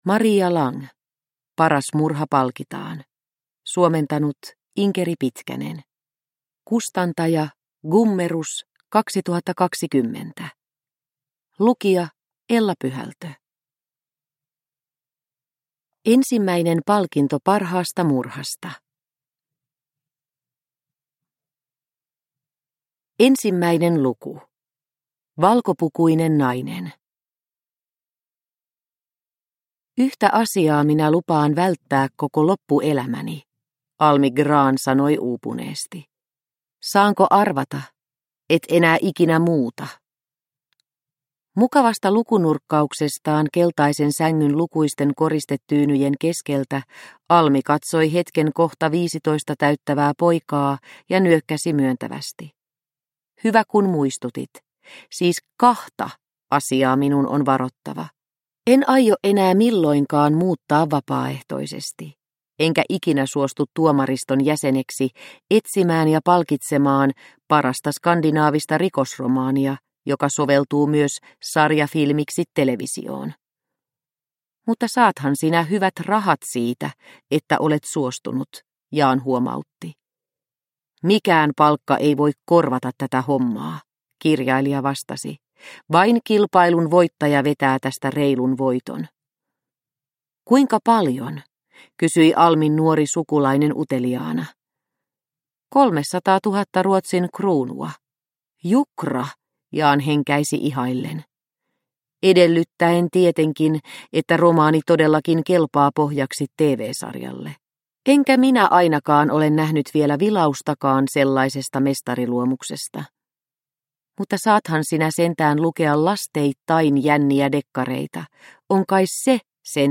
Paras murha palkitaan – Ljudbok – Laddas ner